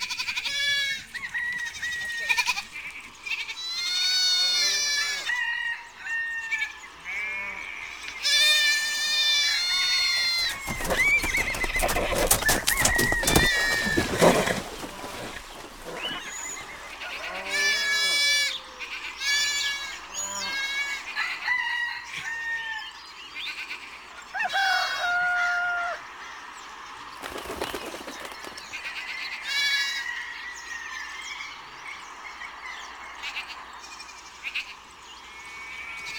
farm.ogg